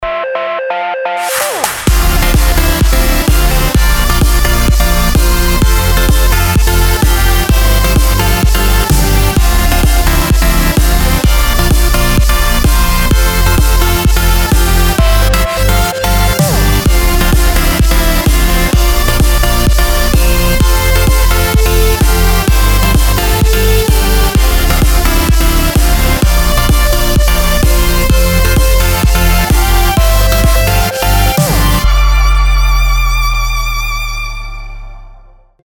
• Качество: 320, Stereo
громкие
dance
без слов
club
progressive house